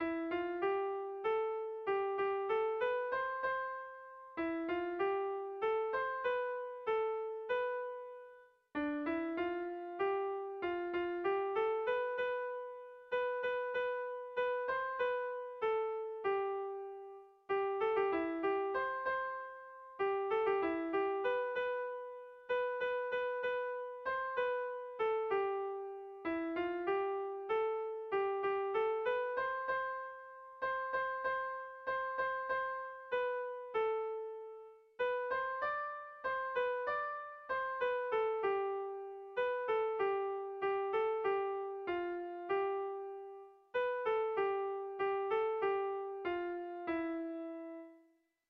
Dantzakoa
ABDE..